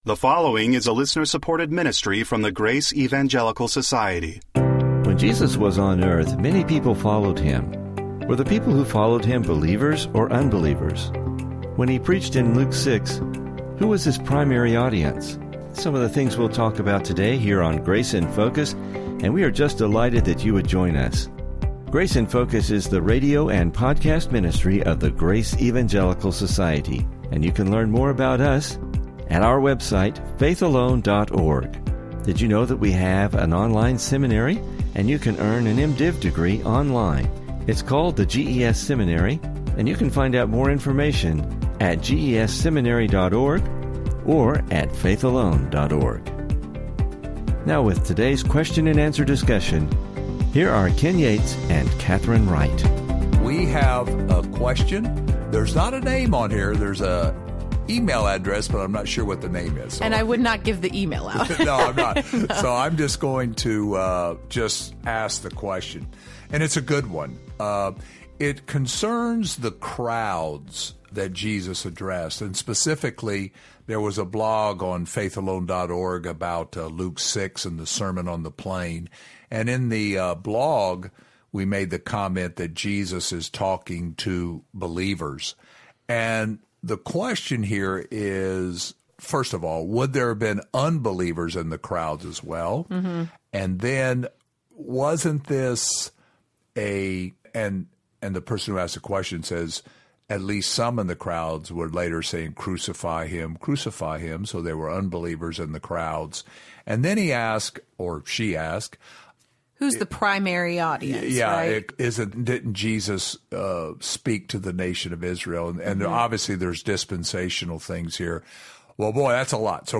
Jesus had a number of audiences and crowds that were interested in Him. These crowds often included both believers and unbelievers. Please listen for an interesting discussion and lessons related to this passage.